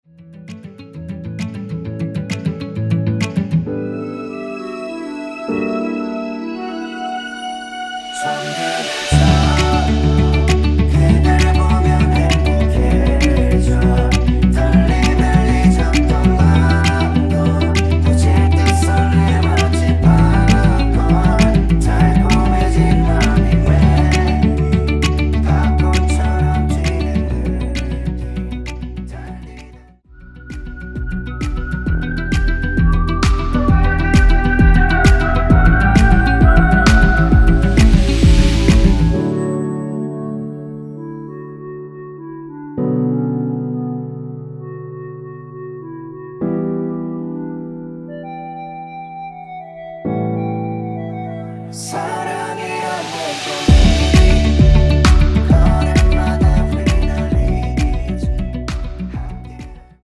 원키 멜로디와 코러스 포함된 MR입니다.
앞부분30초, 뒷부분30초씩 편집해서 올려 드리고 있습니다.
중간에 음이 끈어지고 다시 나오는 이유는